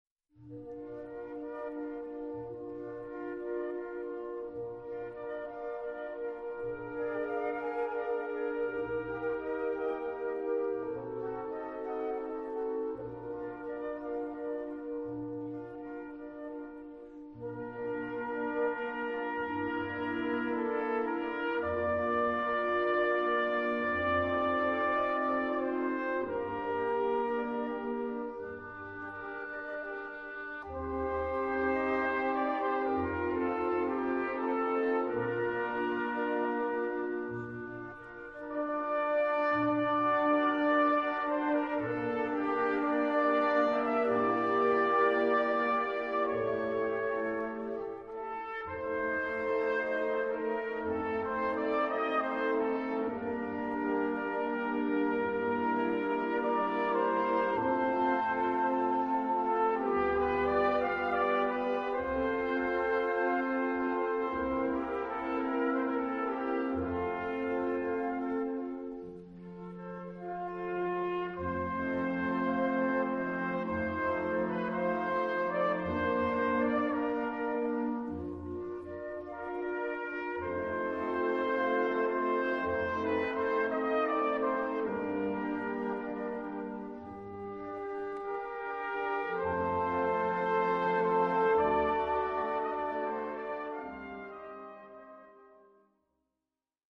Gattung: Kirchliche Blasmusik
Besetzung: Blasorchester